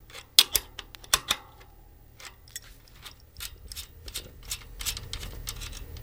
Axle Nut Unscrew